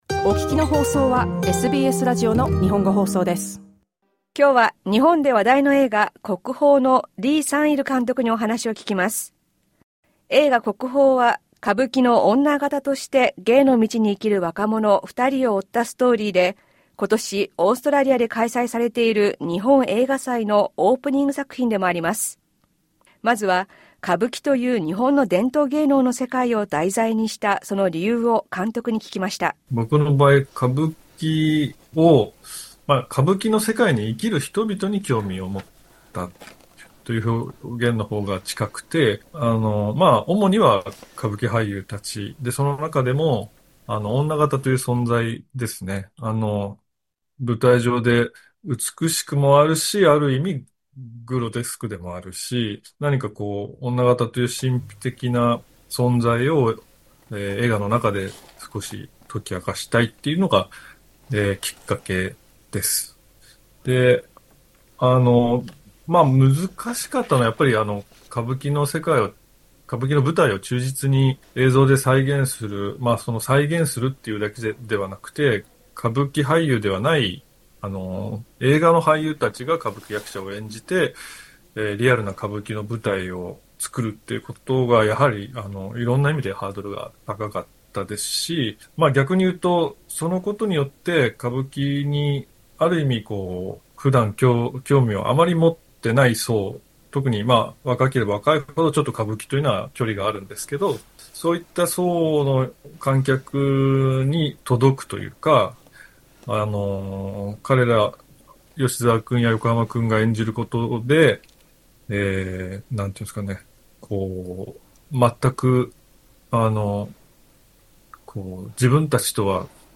Interview with director Sang-il Lee on "Kokuhō"
Let’s listen again to our interview with director Lee Sang-il.